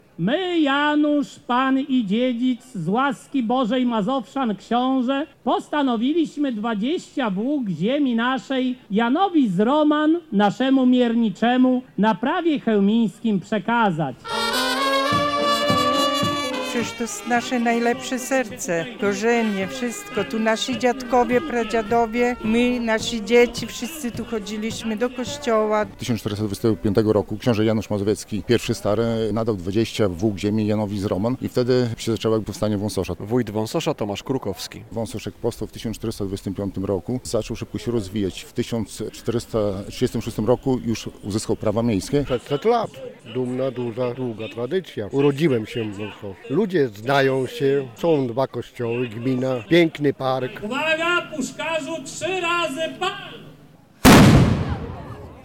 Wszyscy tu chodziliśmy do kościoła - mówi mieszkaniec Wąsosza.
Szybko się rozwijał, bo leżał przy skrzyżowaniu szlaków handlowych - mówi wójt Wąsosza Tomasz Krukowski.